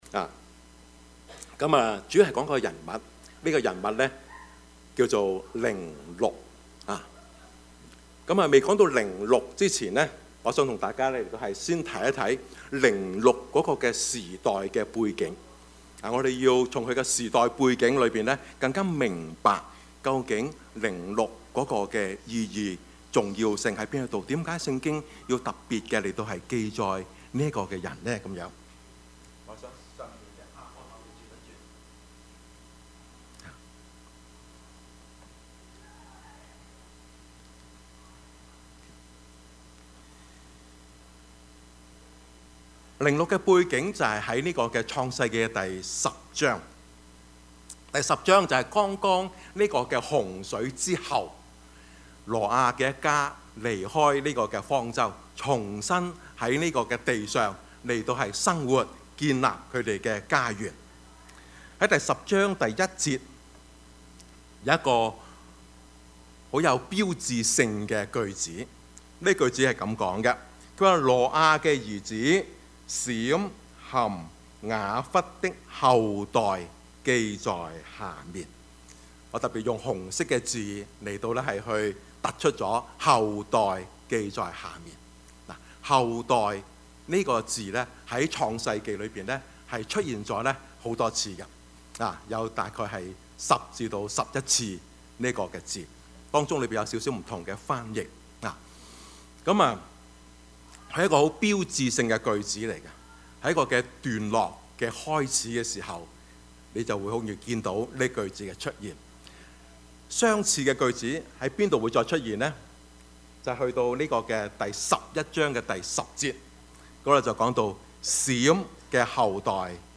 Service Type: 主日崇拜
Topics: 主日證道 « 孫中山先生(三 ) 異象, 使命, 行動 »